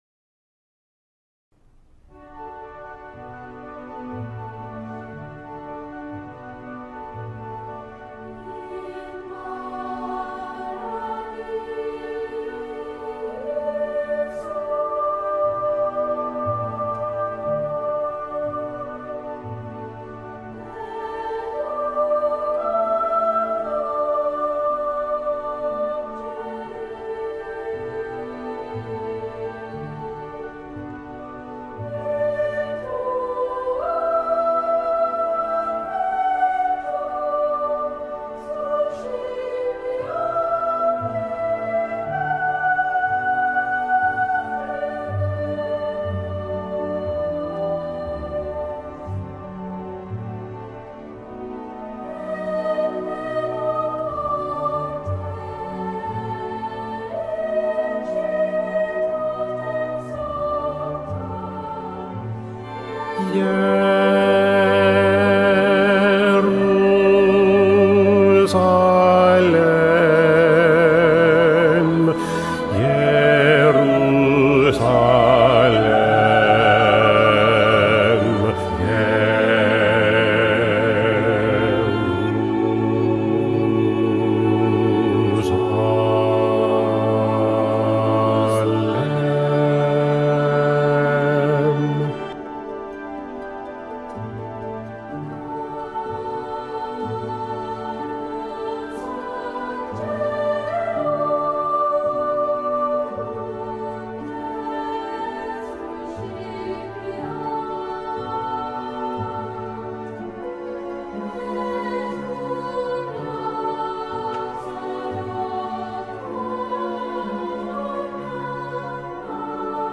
-Material didáctico-
Bajo II